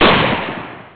e-gun02.wav